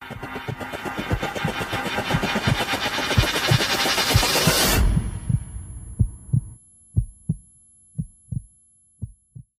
portalout.mp3